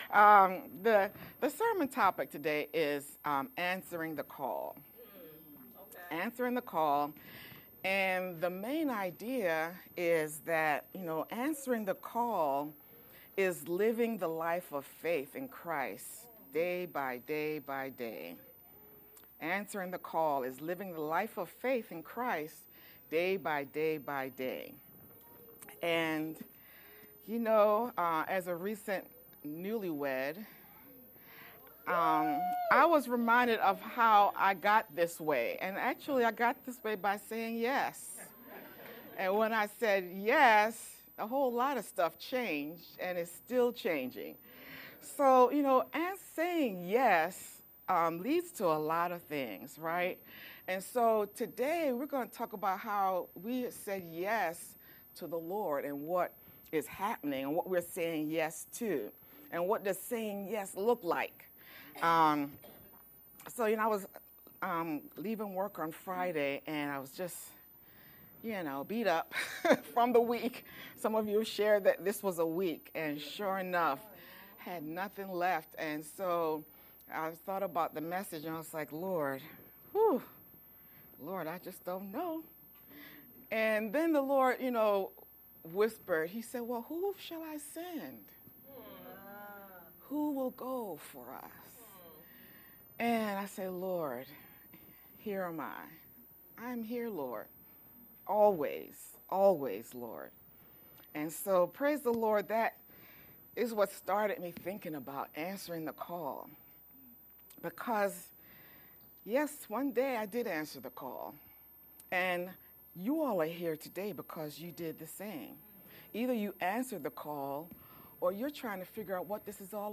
VBCC-edited-june-29th-sermon-only_Converted.mp3